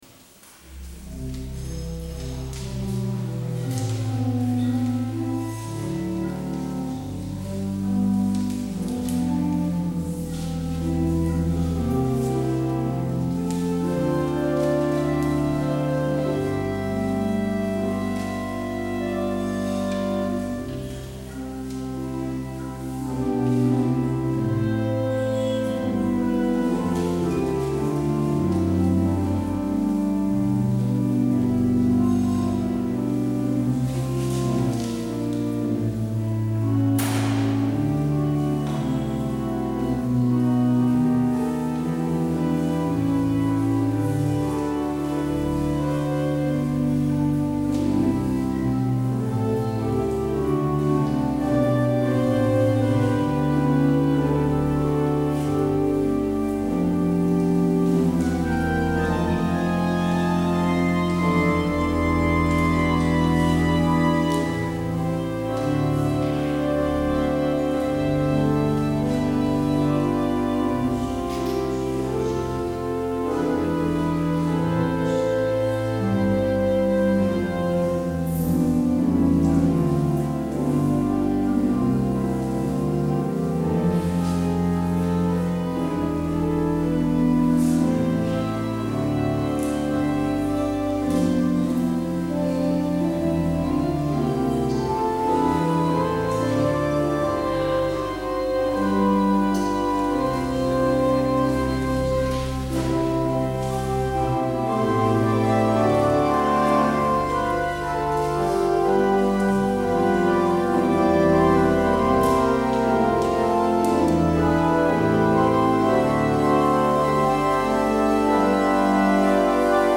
 Luister deze kerkdienst terug
Het openingslied is Heer, raak mij aan met uw adem (Lied 695: 1, 2, 5).